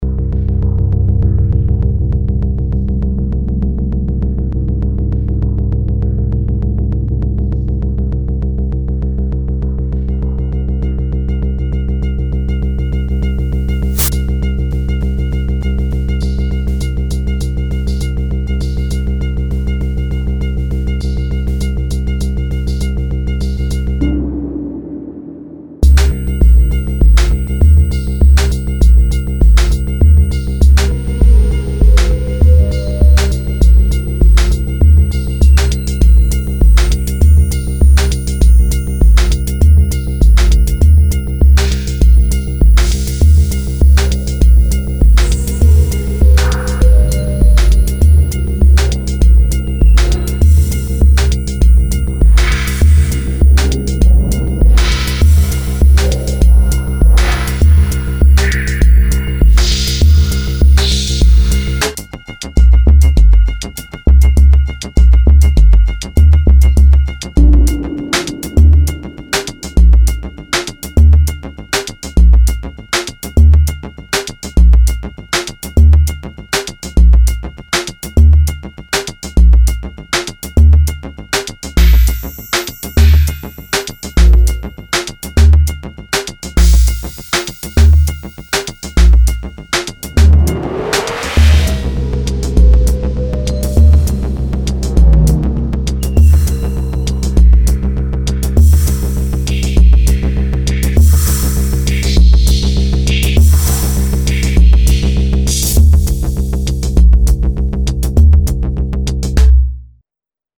pour électronique